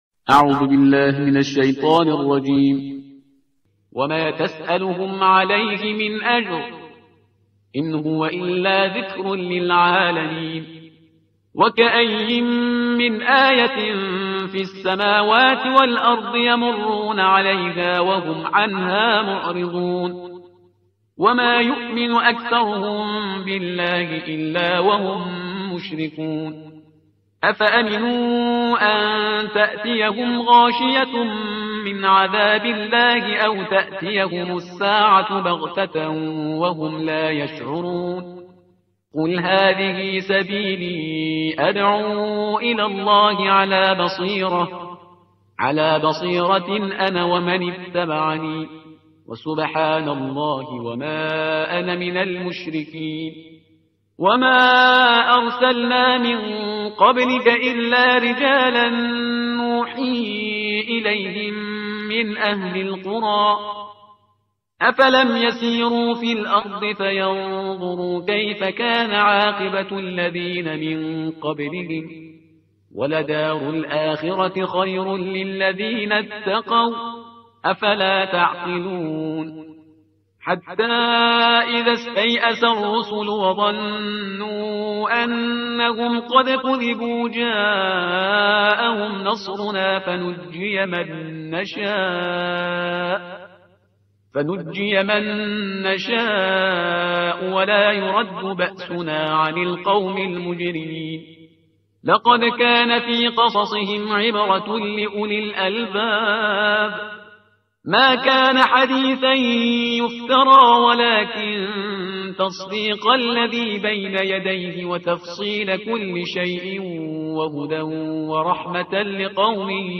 ترتیل صفحه 248 قرآن – جزء سیزدهم